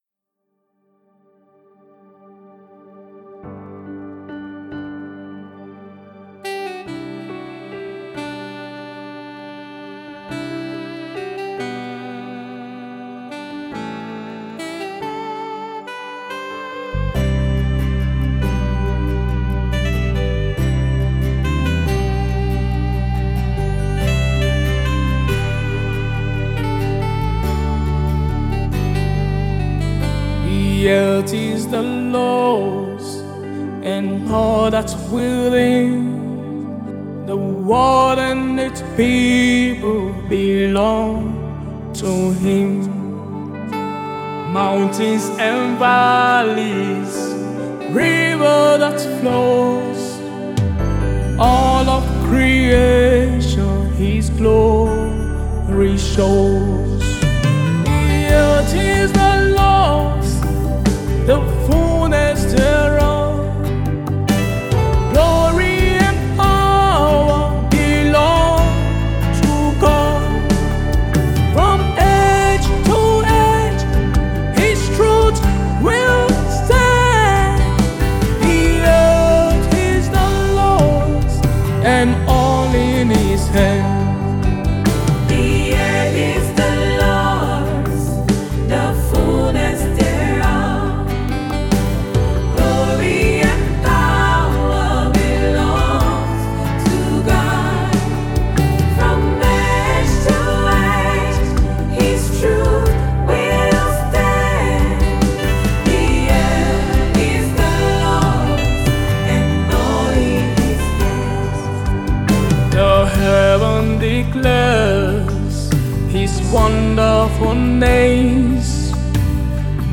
powerful vocals and heartfelt delivery